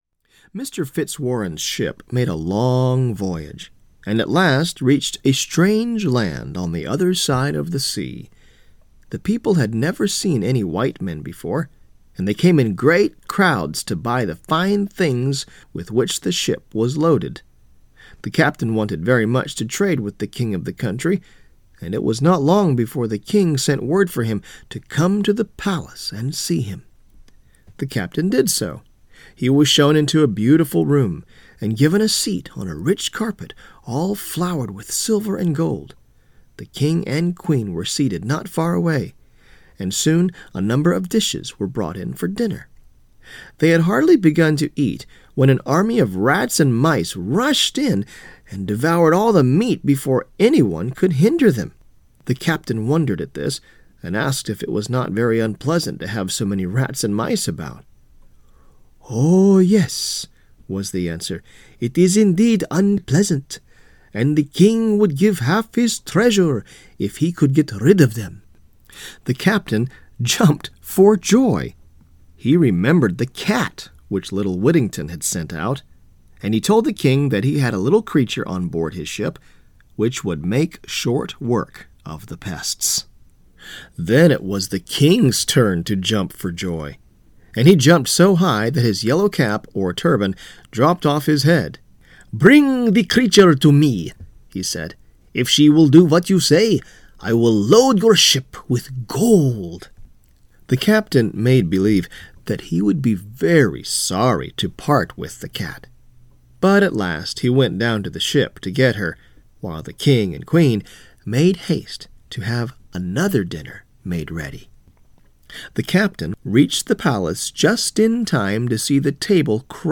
with varying accents for the different characters